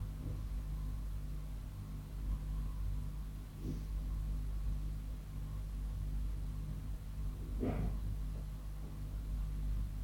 2. Bruit de craquements de l'enceinte branchée dans la cuisine placée dans la cuisine ;
Le bruit est notablement faible lorsque l'enceinte est dans la cuisine branchée dans la cuisine.
2-bruit-enceinte-branchee-dans-la-cuisine-placee-dans-la-cuisine.wav